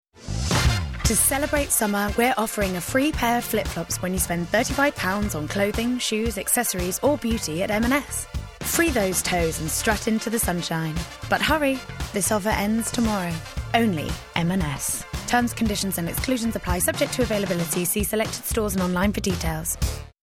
20/30's London/Neutral,
Contemporary/Natural/Earthy
Commercial Showreel